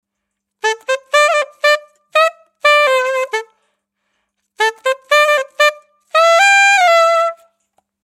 描述：10首高音萨克斯的时髦旋律适用于十月的种子循环树
标签： 120 bpm Funk Loops Woodwind Loops 1.38 MB wav Key : Unknown
声道立体声